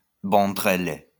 Bandrele (French pronunciation: [bɑ̃dʁele]